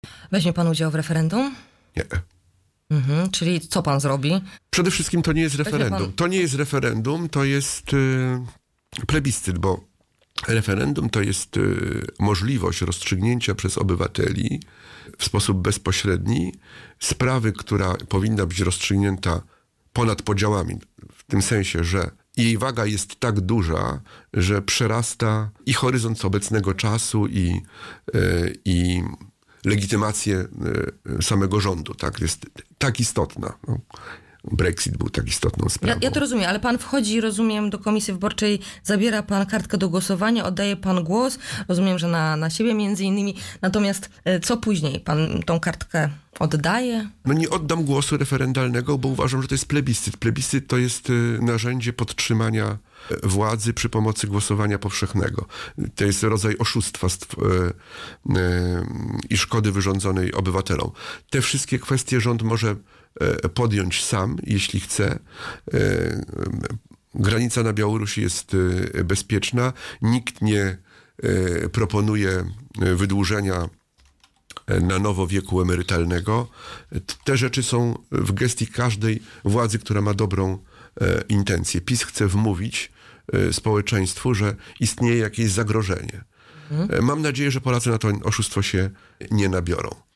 Mam nadzieję, że Polacy na to oszustwo się nie nabiorą, -Marzę o tym, by za jakiś czas mur na granicy białoruskiej przestał istnieć, – W szkołach istnieją toksyczne relacje – mówił w audycji „Poranny Gość” senator K.M Ujazdowski.
-Nie wezmę udziału w referendum –powiedział na naszej antenie.